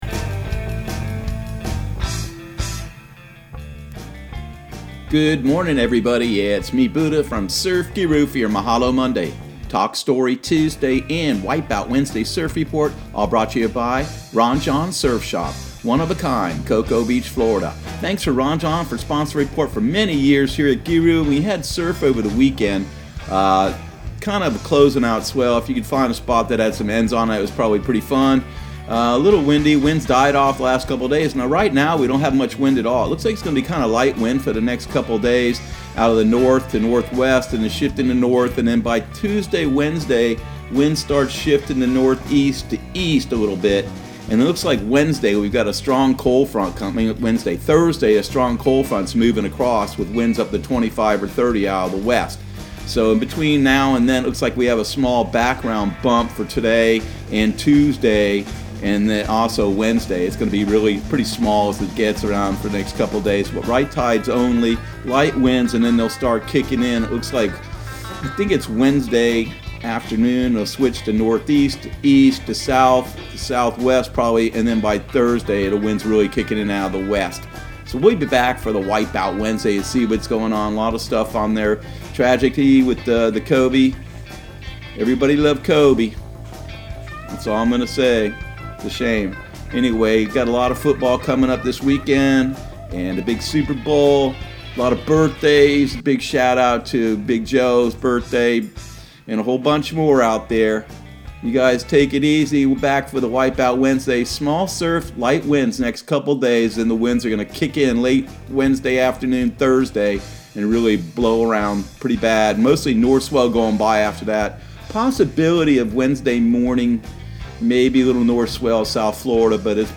Surf Guru Surf Report and Forecast 01/27/2020 Audio surf report and surf forecast on January 27 for Central Florida and the Southeast.